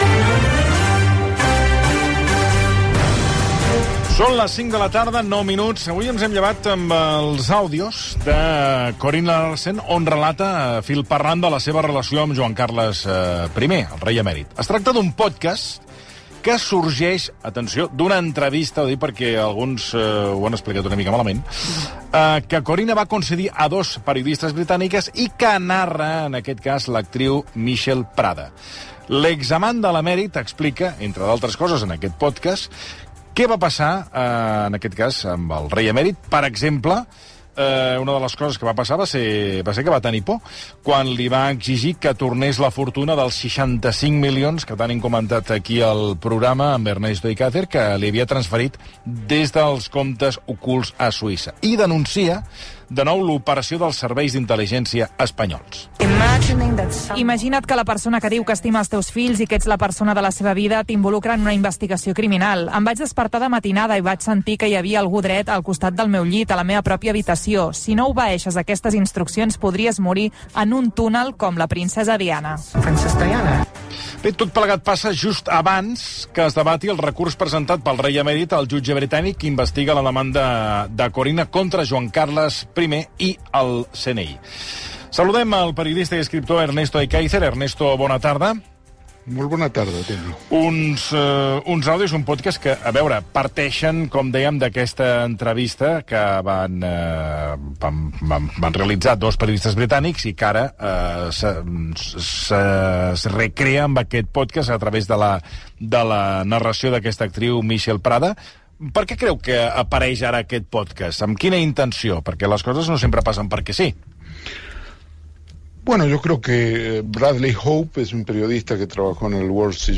Conversación entre los periodistas Ernesto Ekaizer y Toni Clapés en ‘Versión RAC1’ sobre las novedades de la ‘Operación Cataluña’, la renovación de los órganos del Poder Judicial, la reforma del delito de sedición y los nuevos audios de Corinna Larsen.